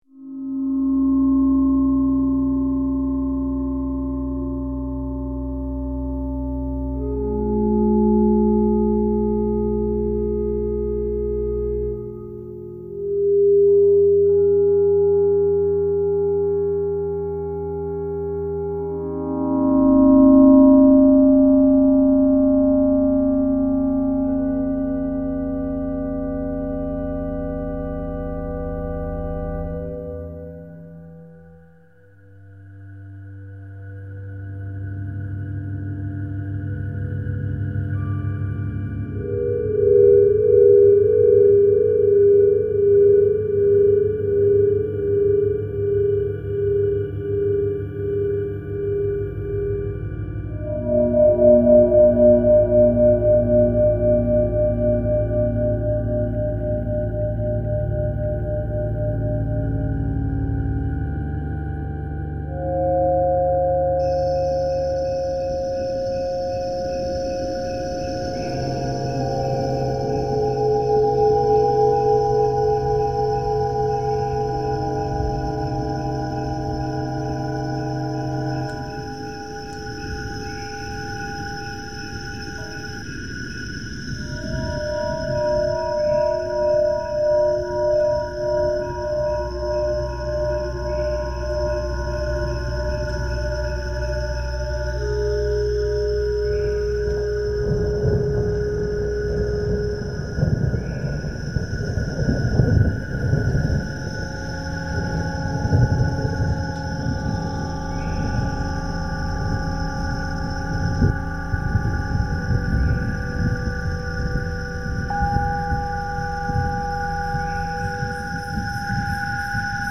a forest soundscape
with birds, shifting air, and the distant rumble of a storm.